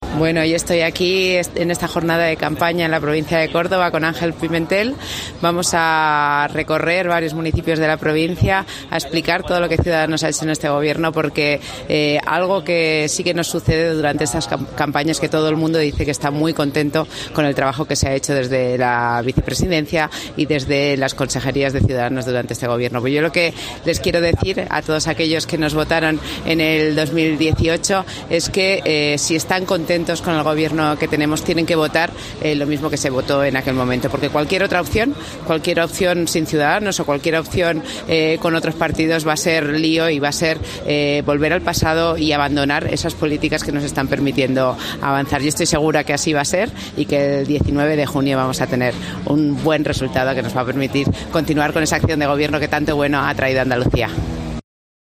La secretaria general del partido ha asegurado en Puente Genil que "cualquier otra opción sin Ciudadanos, o cualquier opción con otros partidos, va a ser lío"